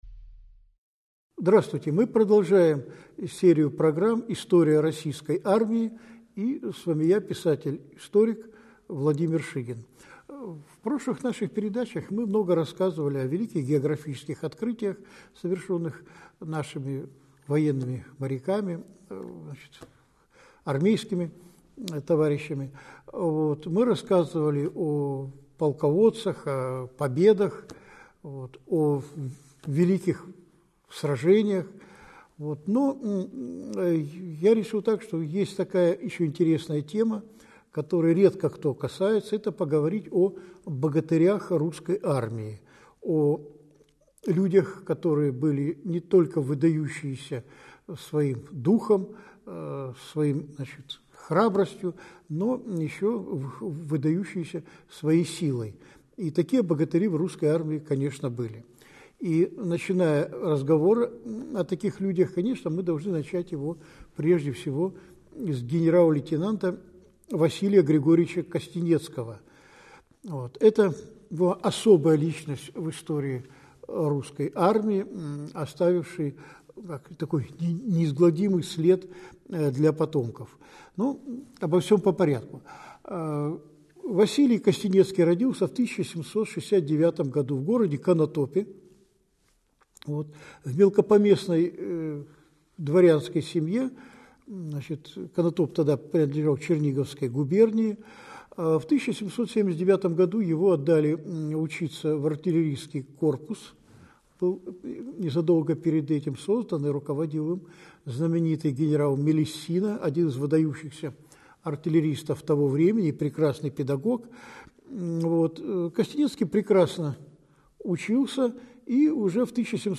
Аудиокнига Богатыри Русской армии. Генерал Костенецкий | Библиотека аудиокниг